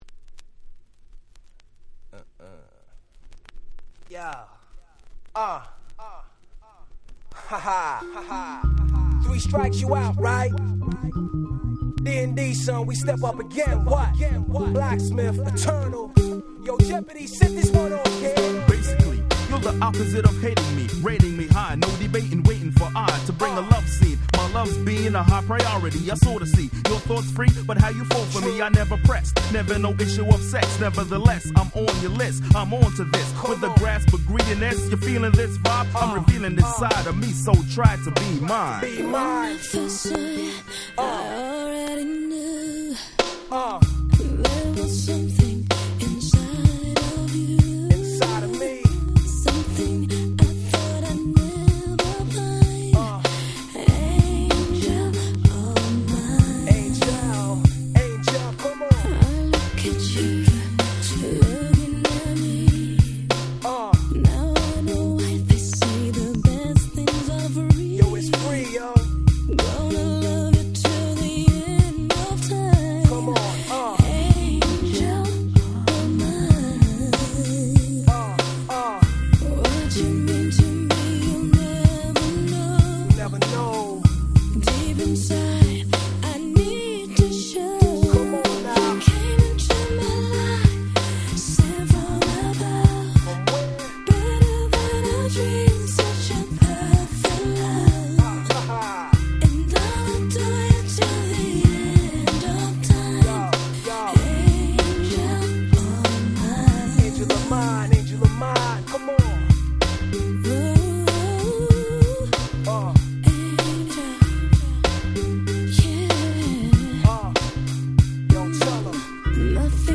97' Nice R&B !!